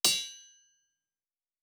Blacksmith 3_10.wav